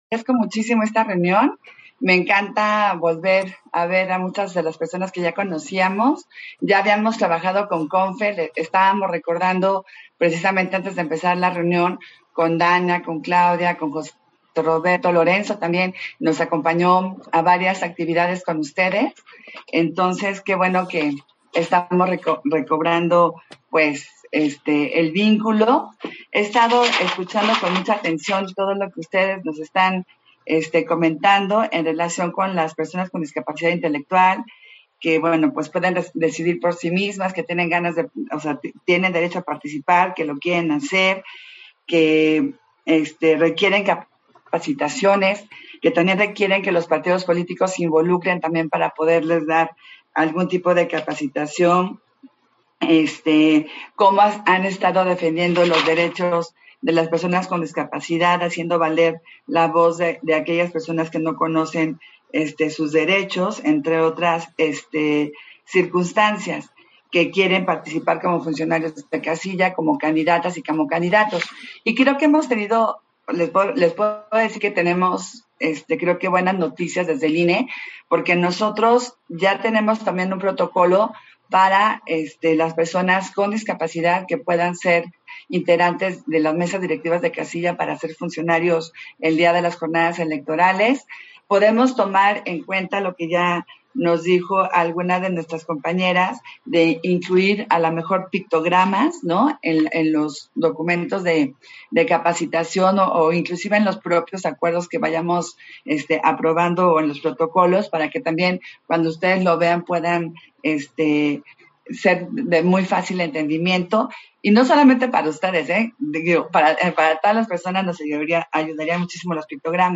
Intervenciones de Consejeras y Consejeros del INE